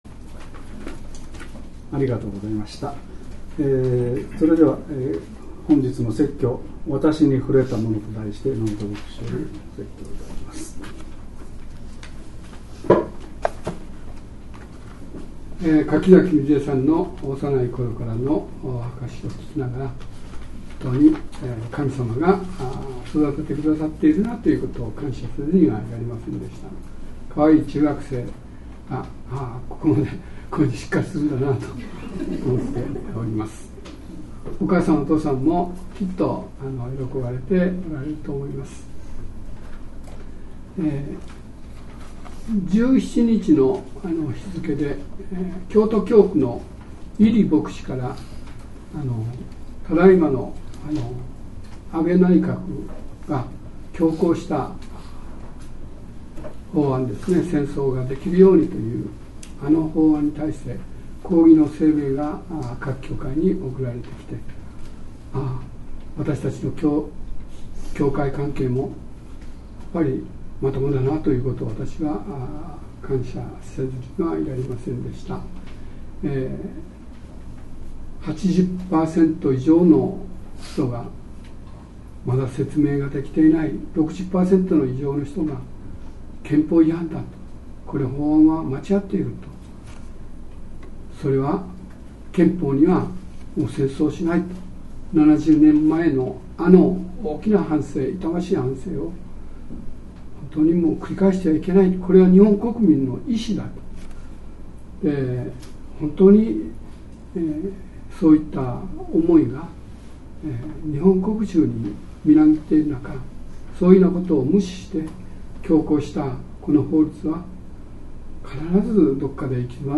説教要旨 | 日本基督教団 世光教会 京都市伏見区
聖霊降臨節第9主日礼拝